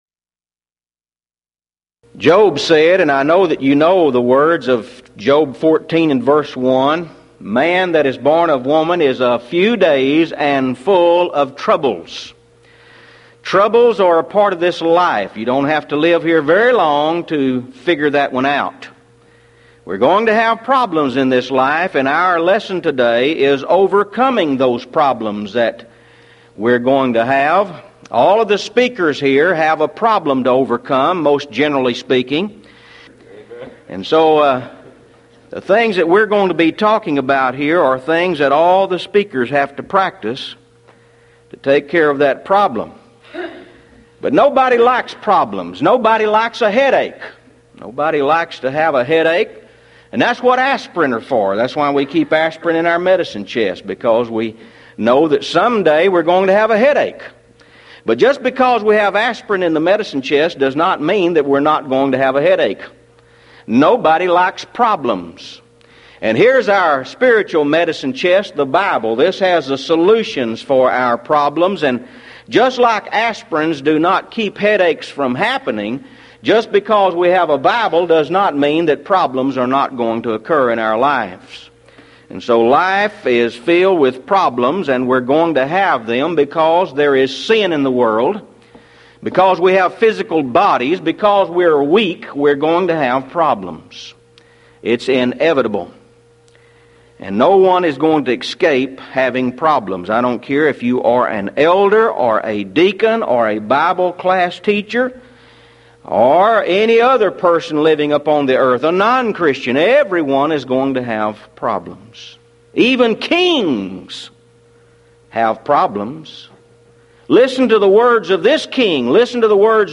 Event: 1993 Mid-West Lectures